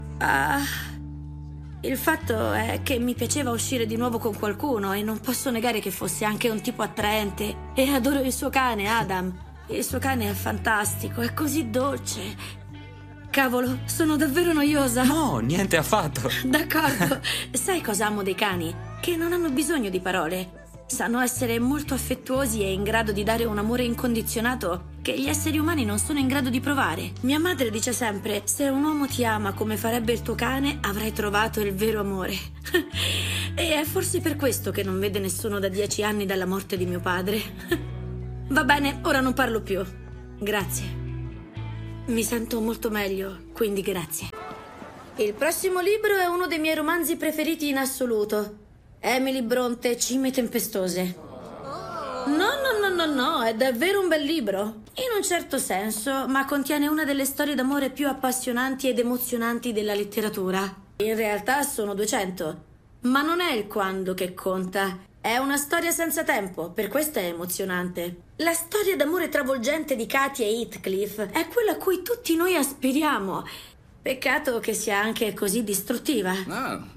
nel film TV "Tutti i cani dei miei ex", in cui doppia Erika Christensen